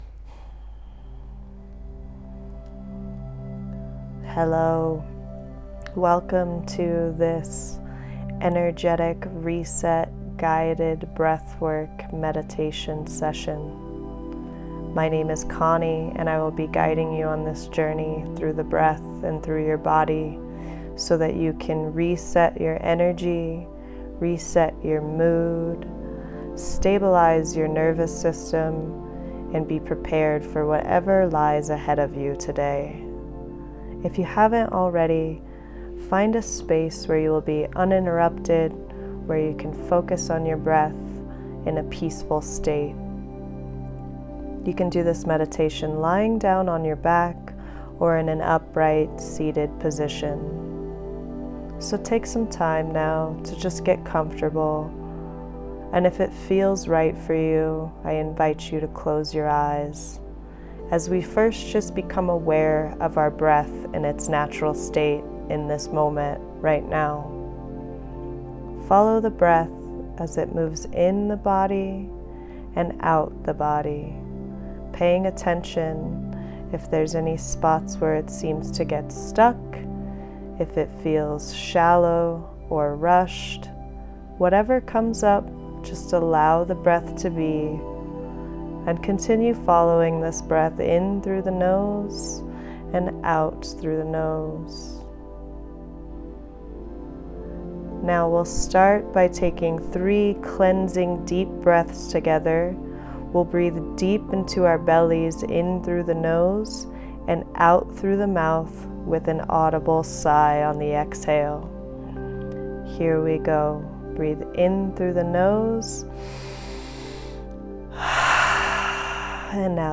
Energy Clearing Breath Work Meditation Download Page